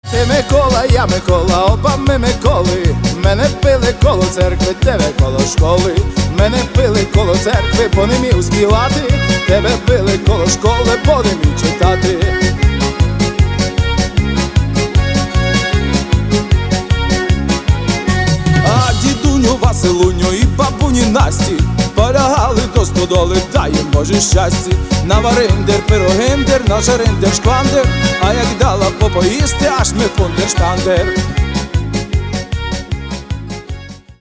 Каталог -> MP3-CD -> Народная